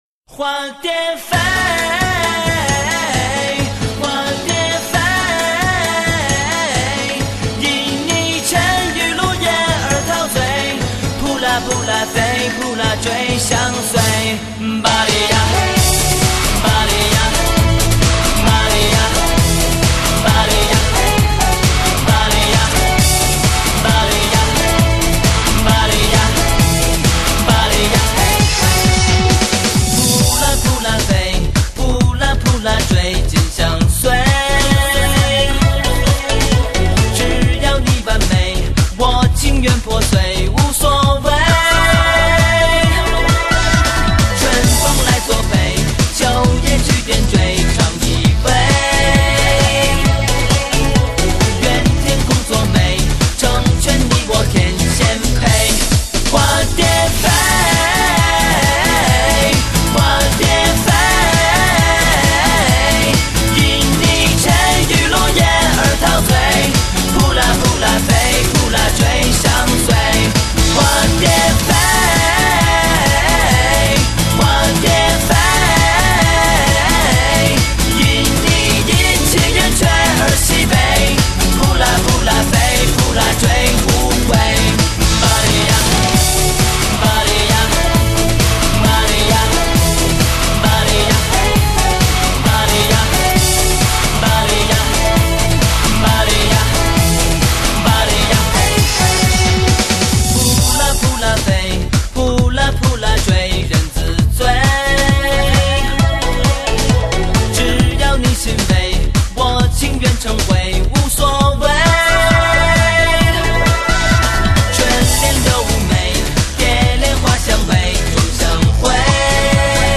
ちょっと聞いたことがあるフレーズが出てきます